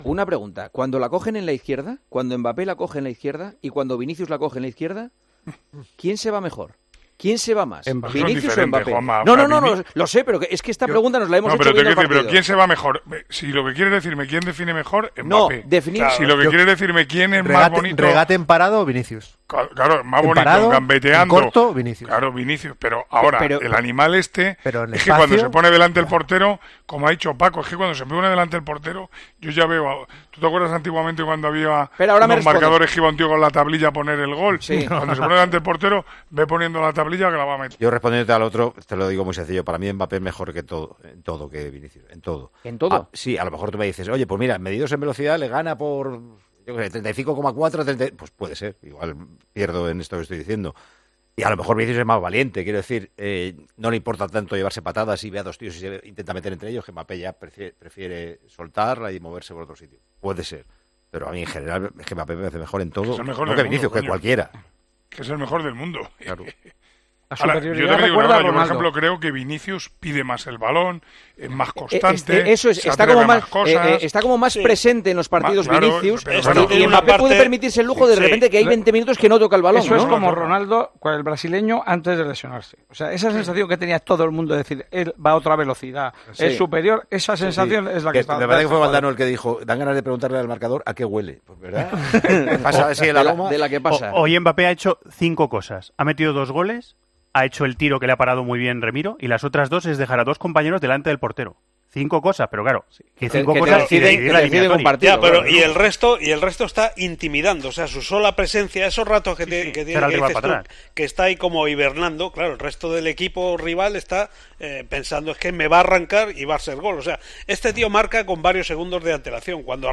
AUDIO: Juanma Castaño preguntó quién desequilibra más entre Kylian Mbappé y Vinicius cuando reciben el balón y los tertulinos respondieron.